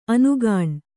♪ anugāṇ